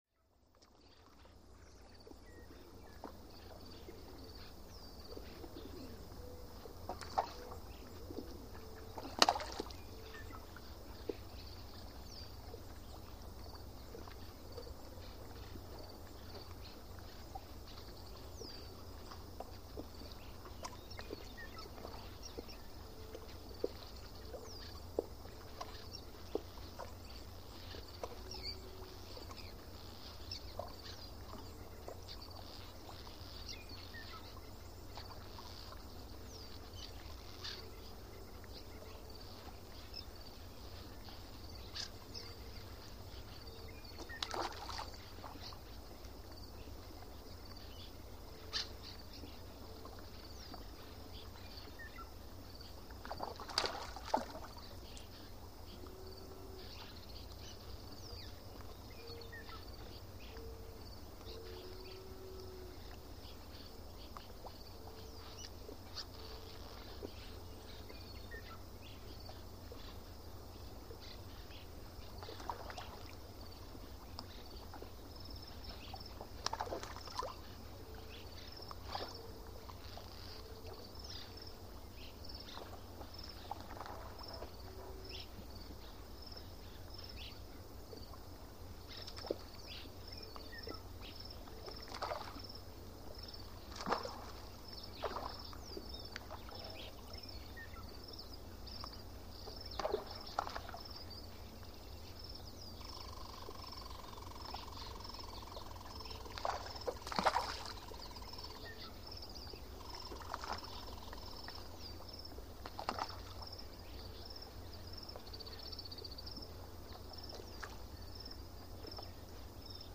Night Ambience | Sneak On The Lot
Light Bird Chirps, Water Splashes And Movement, And Insect Bed Throughout.